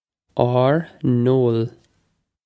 Pronunciation for how to say
awr nohl
This is an approximate phonetic pronunciation of the phrase.